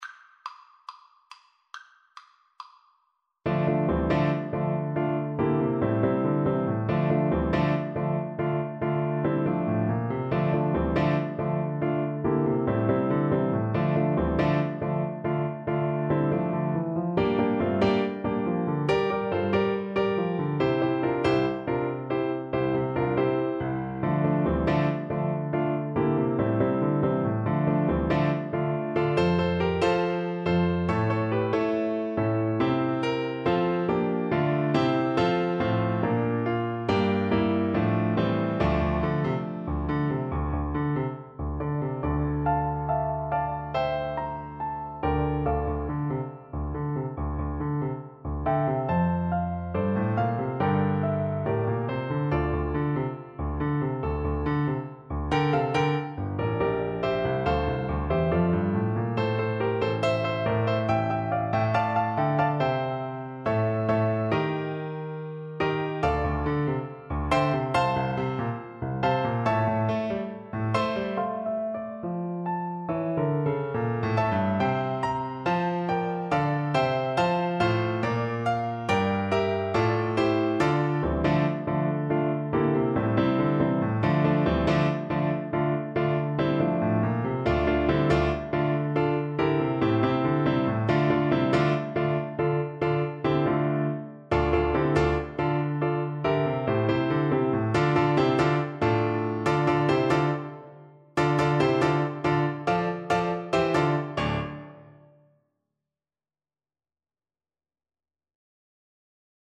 with a rock twist
4/4 (View more 4/4 Music)
Driving forward = c. 140
Christmas (View more Christmas Trombone Music)